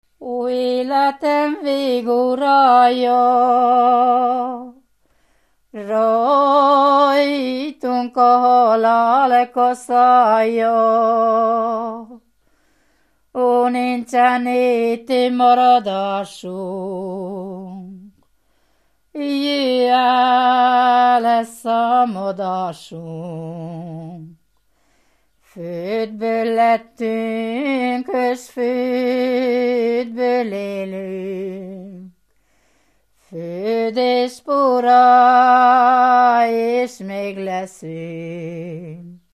Moldva és Bukovina - Moldva - Pusztina
Műfaj: Népének
Stílus: 4. Sirató stílusú dallamok